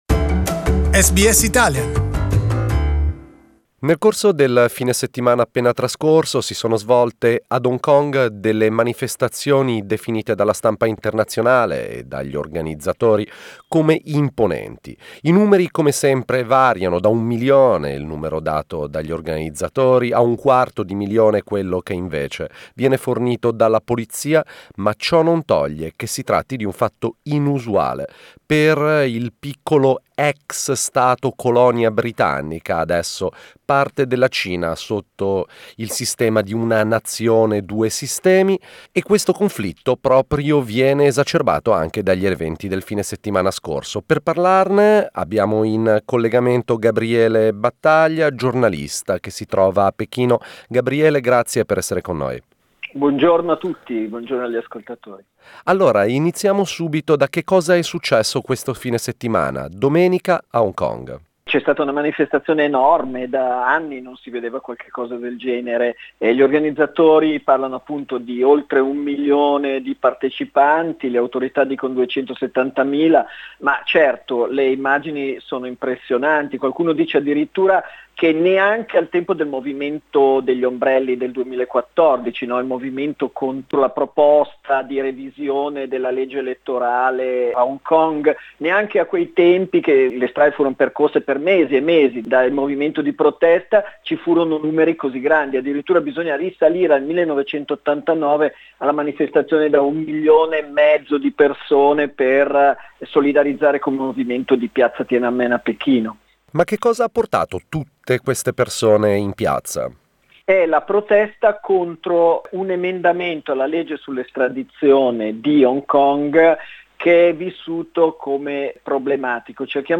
Beijing based journalist